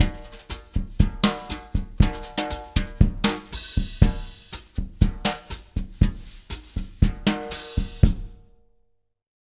120BOSSA06-R.wav